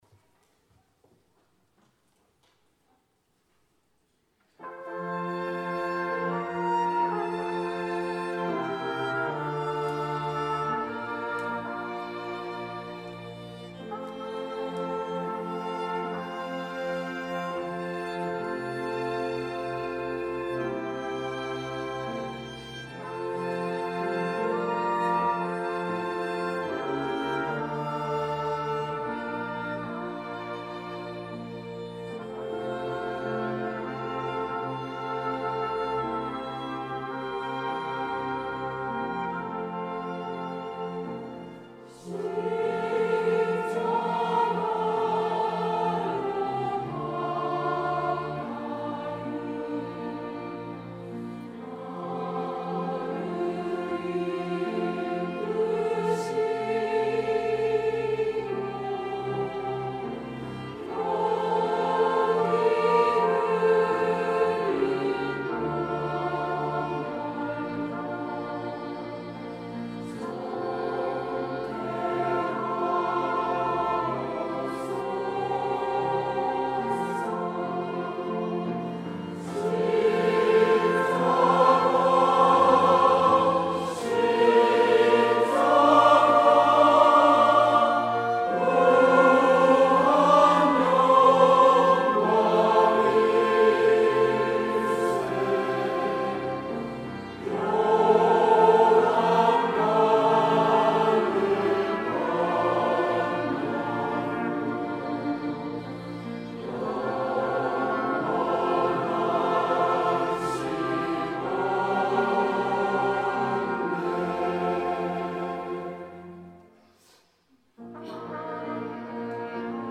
2부 찬양대